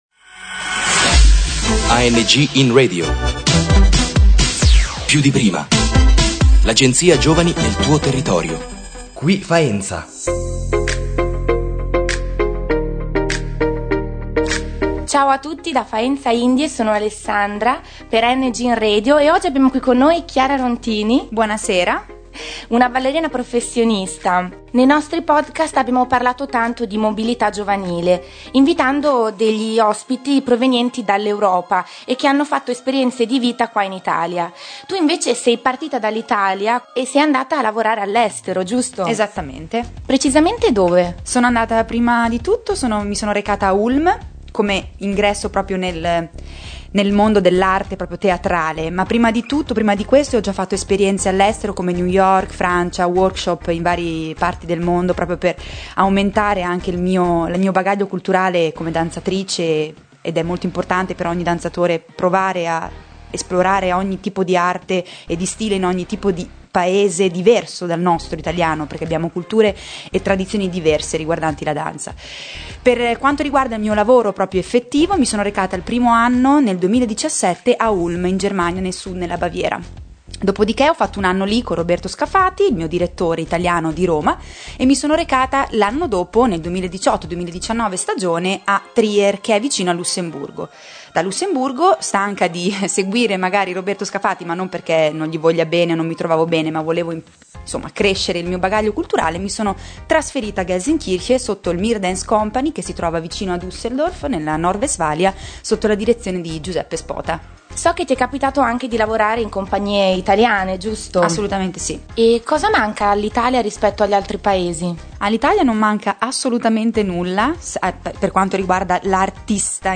AngInRadio Faenza indie: intervista